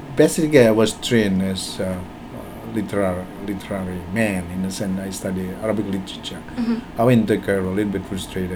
S1 = Indonesian male S2 = Malaysian female Context: S1 is talking about his background in research.
Its pronunciation as [kæro] , and the fact that it was spoken fast and not very loudly may have contributed to the problem.